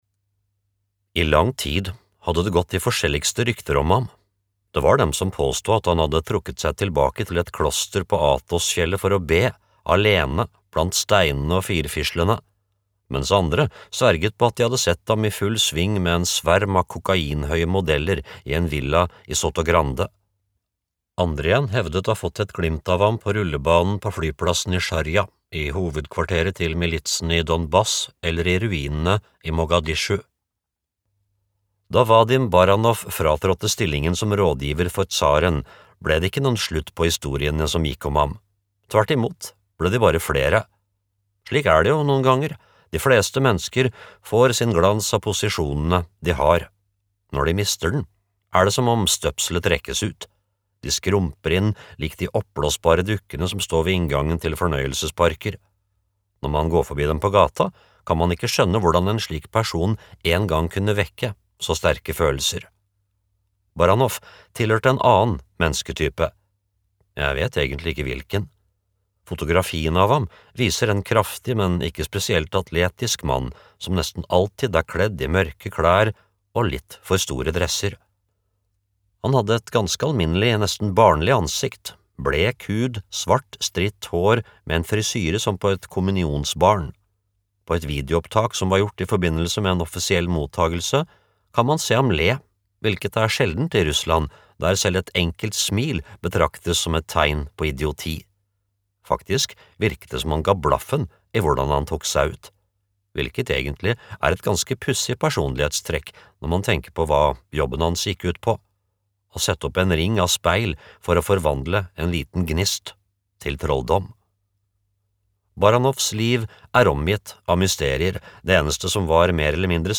Trollmannen fra Kreml (lydbok) av Giuliano Da Empoli